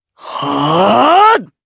Lucias-Vox_Casting4_jp.wav